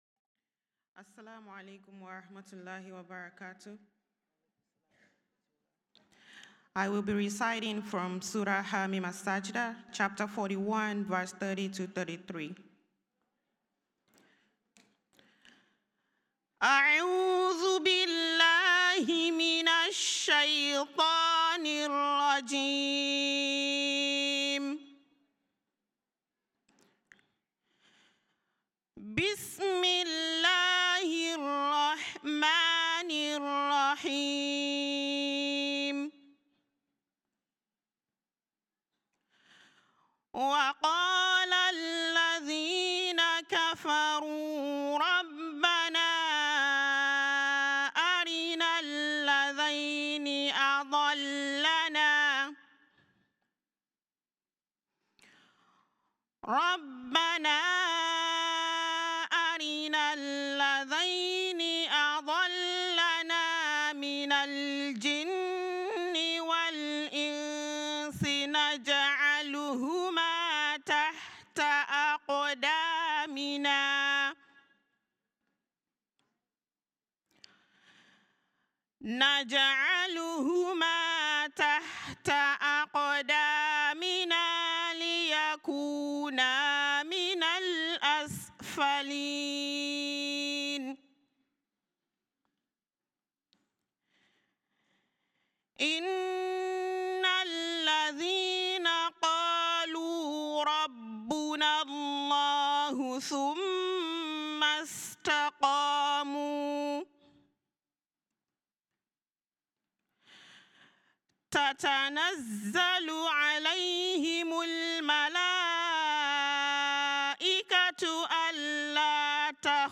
Jalsa Salana 2025: Ladies Session
Tilawat
2025+-+US+Jalsa+Lajna+Morning+Session+Tilawat.mp3